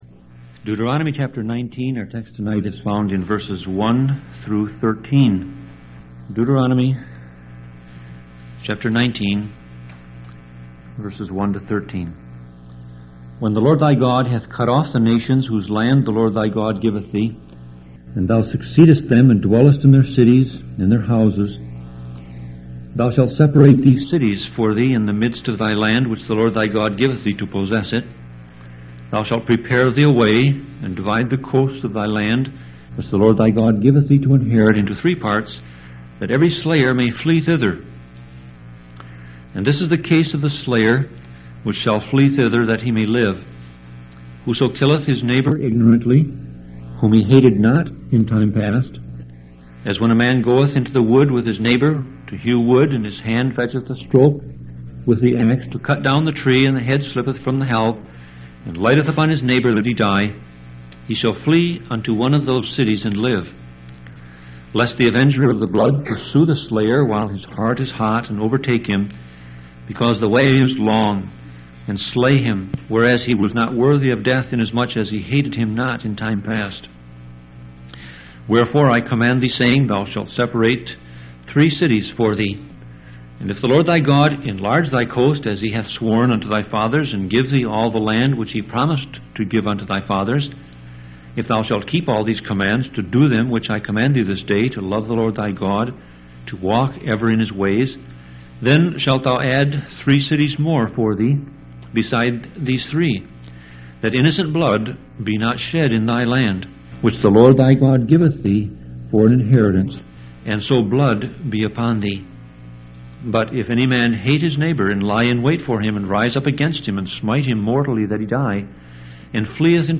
Sermon Audio Passage: Deuteronomy 19:1-13 Service Type